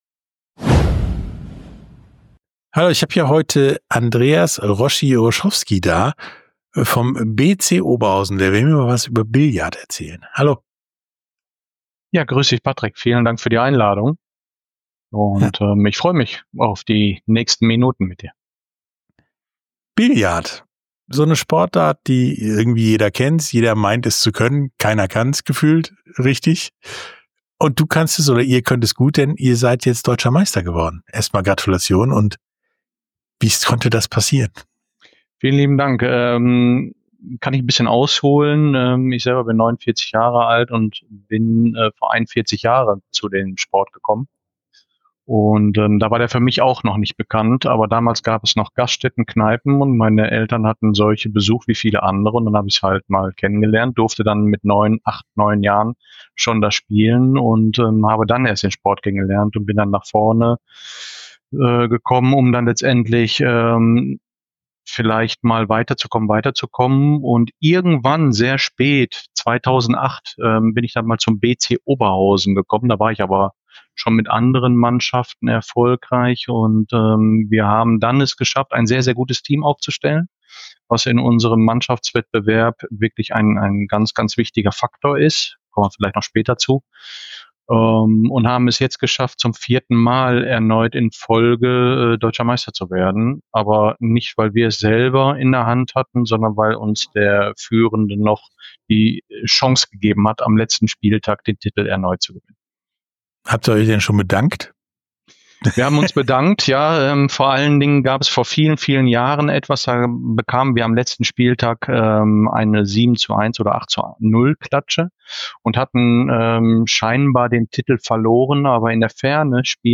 Tauchen Sie ab in die Sportstunde ungeungeschnittentinterviews in ihrer authentischen, ungeschnittenen Langfassung. Diese tiefgründigen Sportstunde Interviews liefern Ihnen nicht nur Fakten, sondern auch einzigartige Einblicke in die Welt des Sports.